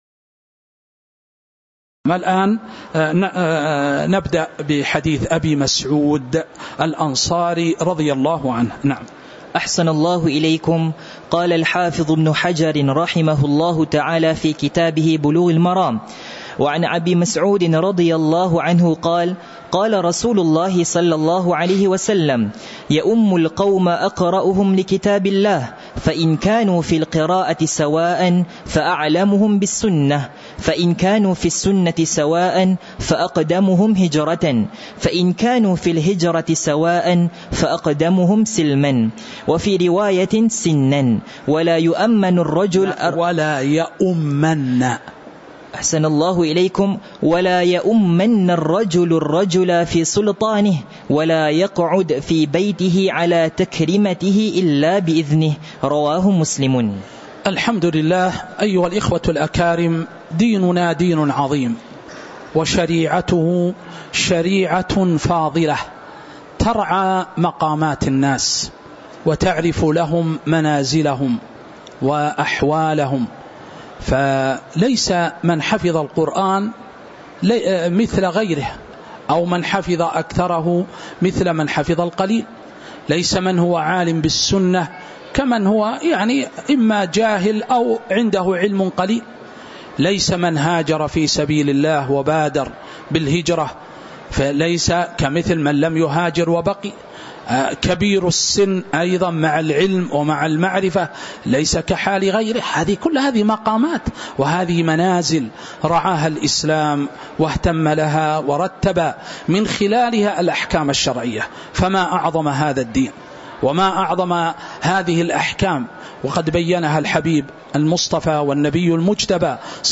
تاريخ النشر ١١ جمادى الآخرة ١٤٤٥ هـ المكان: المسجد النبوي الشيخ